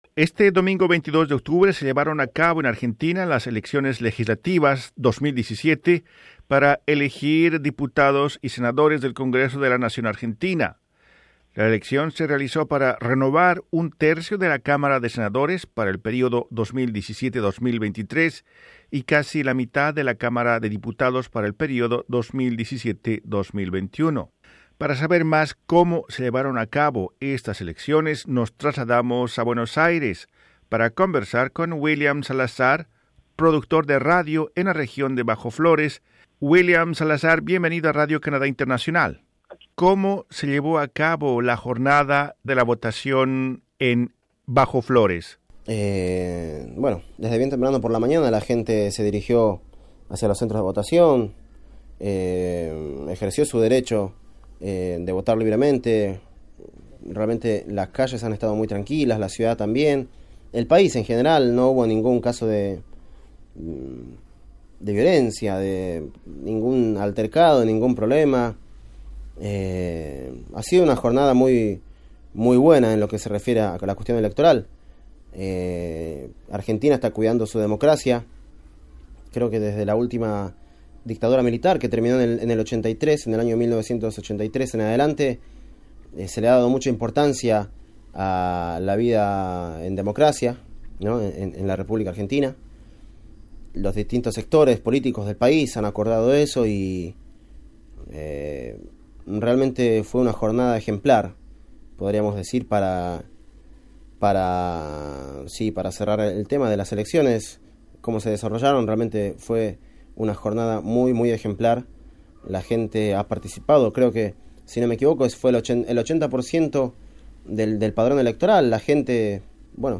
Las elecciones en Argentina vistas por un joven radialista argentino